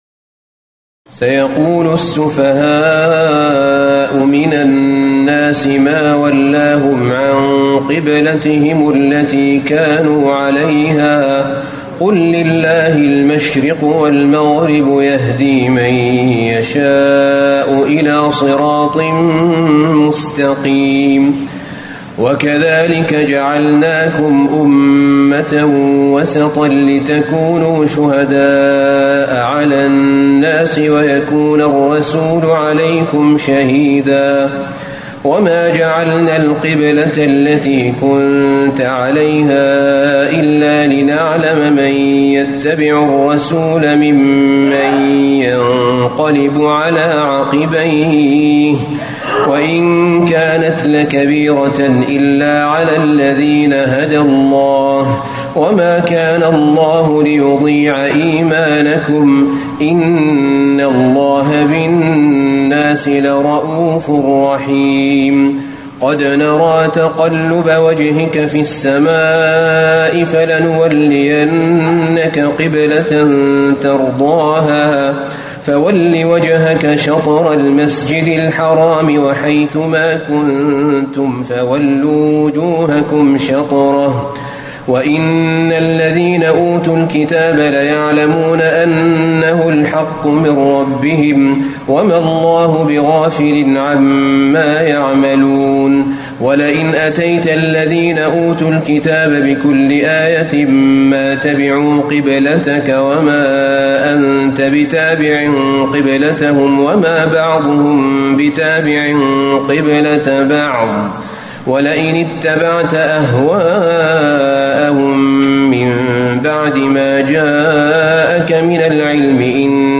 تراويح الليلة الثانية رمضان 1437هـ من سورة البقرة (142-203) Taraweeh 2 st night Ramadan 1437H from Surah Al-Baqara > تراويح الحرم النبوي عام 1437 🕌 > التراويح - تلاوات الحرمين